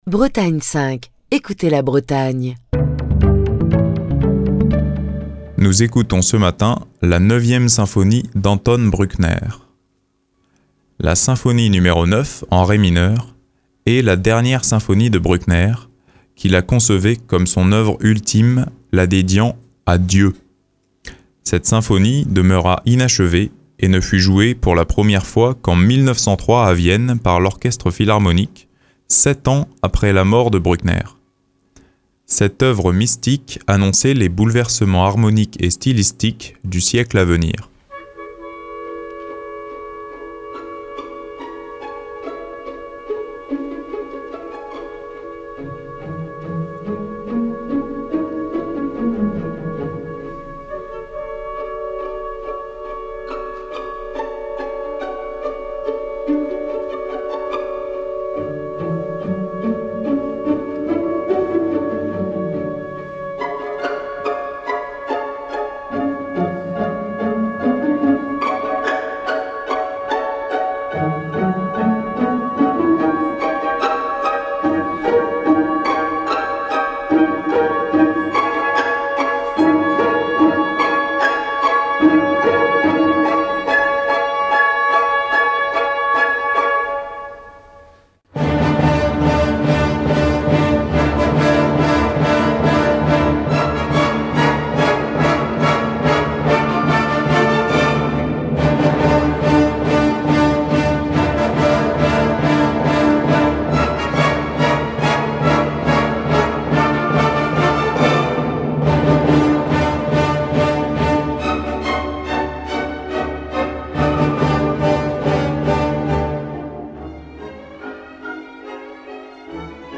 Heureusement, certains enregistrements de ses concerts captés en live nous sont parvenus !